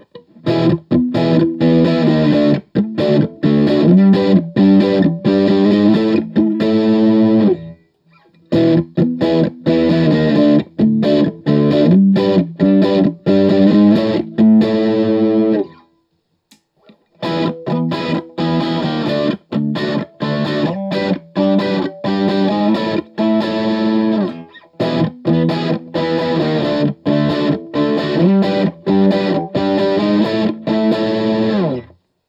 All recordings in this section were recorded with an Olympus LS-10. The amp is an Axe-FX Ultra set to either the “Basic Brit 800” (Marshall JCM-800) or “Tiny Tweed” (Fender Champ) setting.
These pickups are not overly chimey, and they’re not super powerful.
Basic 800
Chords
For each recording, I cycle through all four of the possible pickup combinations, those being (in order): neck pickup, both pickups (in phase), both pickups (out of phase), bridge pickup.